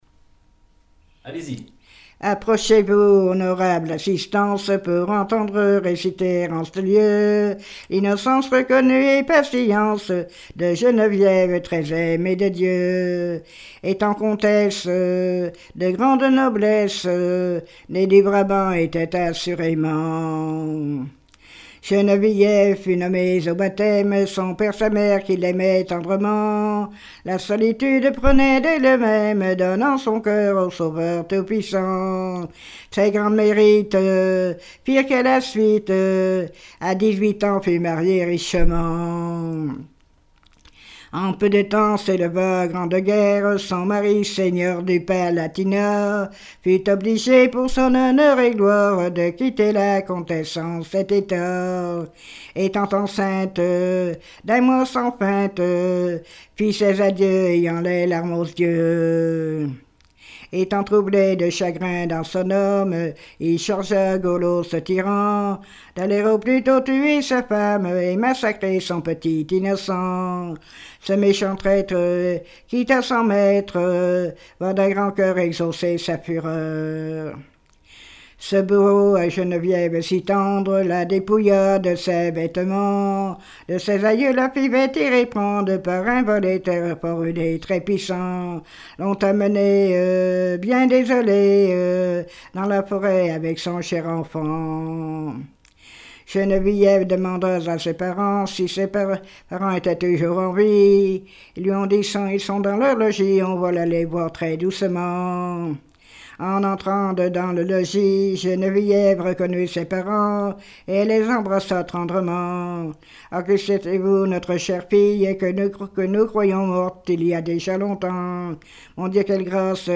Genre strophique
répertoire de chansons populaires
Pièce musicale inédite